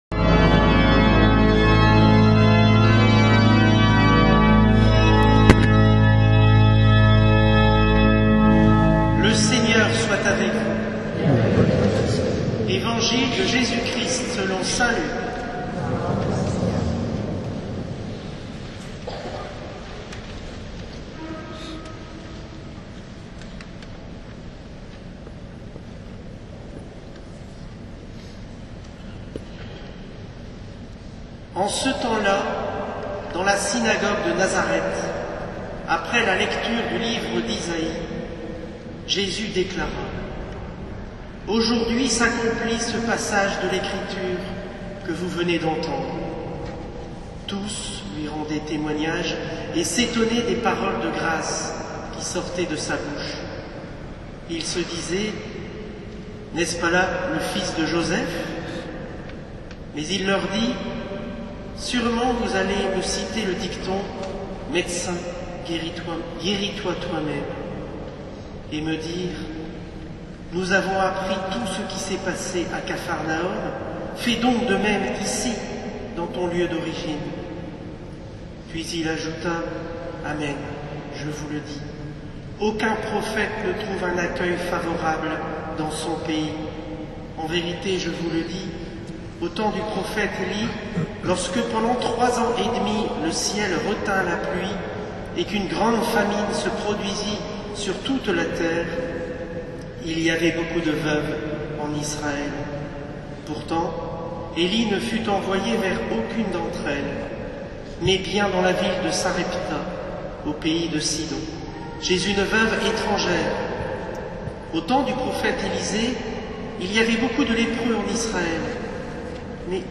Homélie du 03-02-19 : Monseigneur Hérouard | Cathédrale Notre-Dame de la Treille